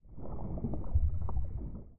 Add footsteps for water - swimming sounds.
I've taken a single, longer audio sample and extracted 3 samples of 2.0 length, and gave them all a 0.5 sec fade-in and fade-out.
This combined with the rate of footstep sounds results in a randomly changing underwater sound that blends in and out somewhat nicely.
default_water_footstep.1.ogg